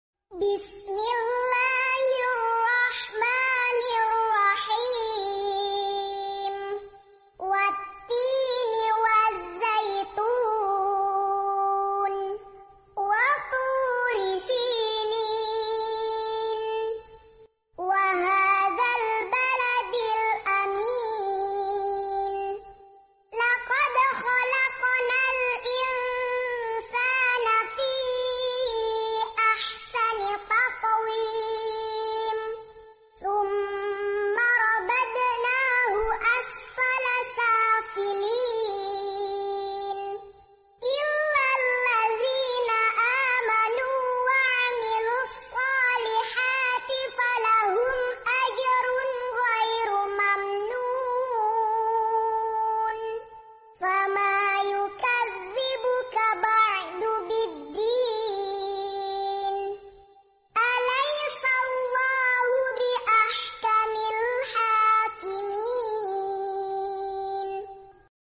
QS 95 AT TIN Bayi Ngaji Metode Ummi Murottal Juz Amma.